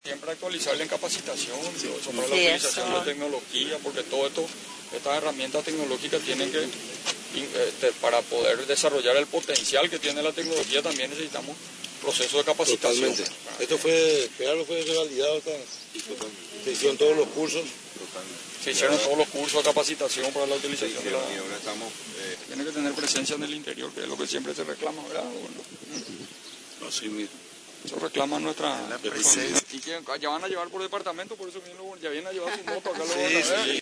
Una flota de 21 nuevas camionetas, 200 motos y unas 640 tablets fueron entregados para los técnicos del Ministerio de Agricultura y Ganadería (MAG), por el Gobierno Nacional, en un acto realizado este martes, en la explanada litoral de la sede gubernativa.